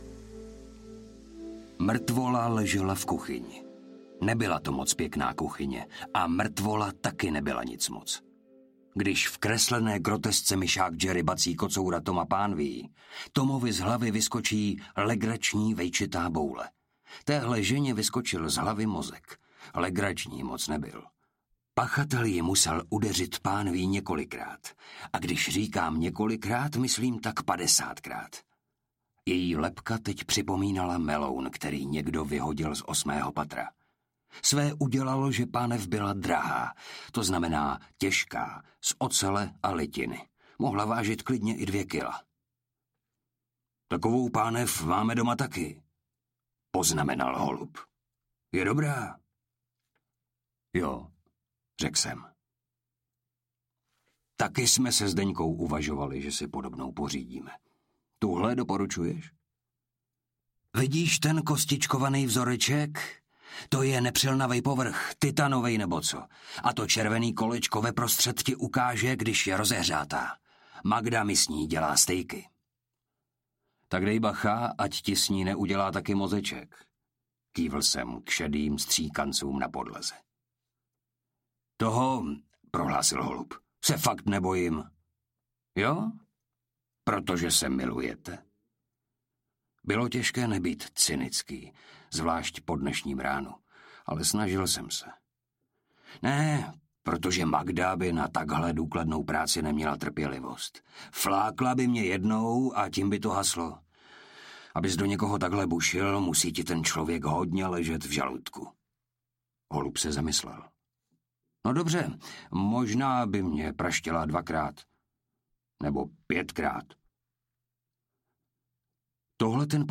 Rychlopalba audiokniha
Ukázka z knihy